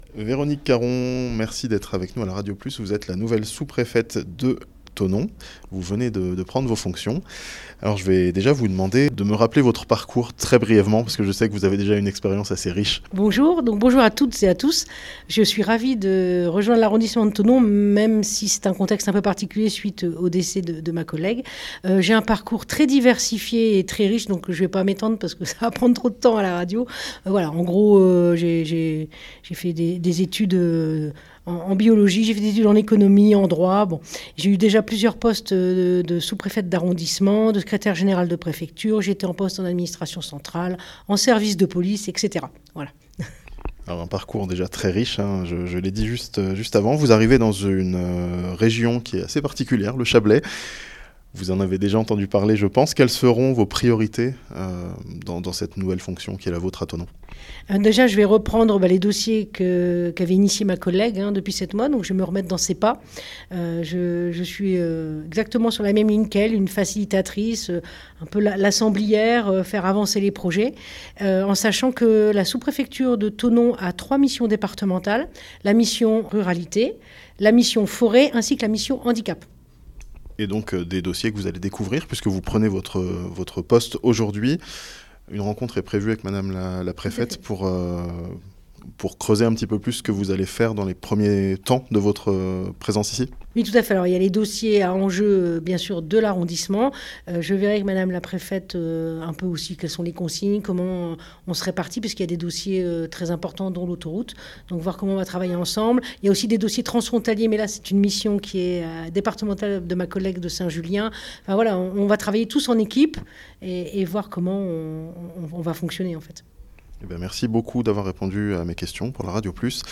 Véronique Caron, nouvelle sous-préfète de Thonon, a pris ses fonctions (interview)